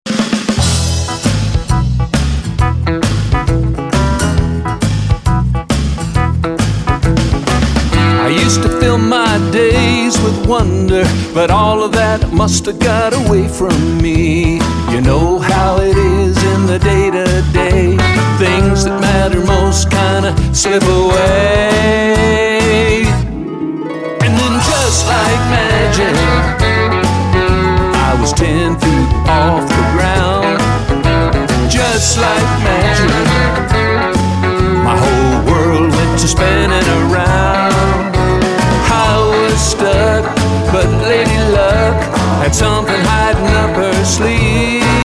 kid funk, blues, pop, rock, country, anthem and ballad